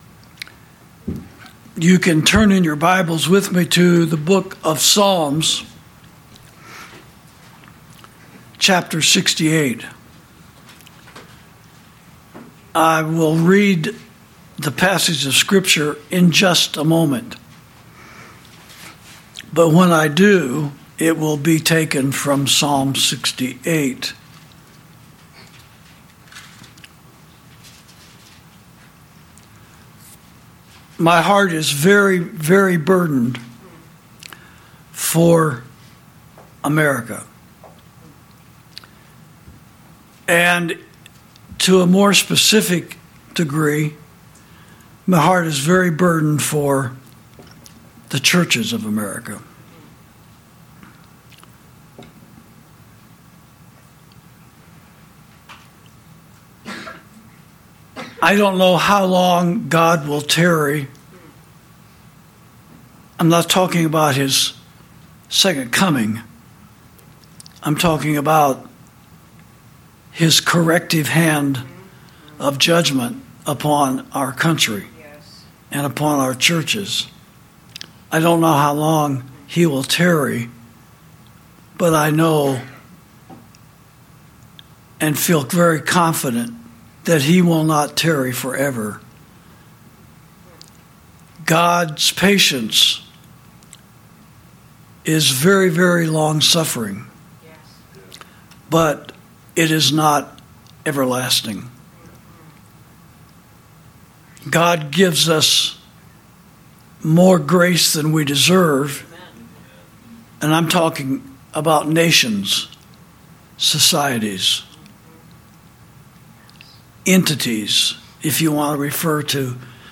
Sermons > The Biblical Remedy For Warmongers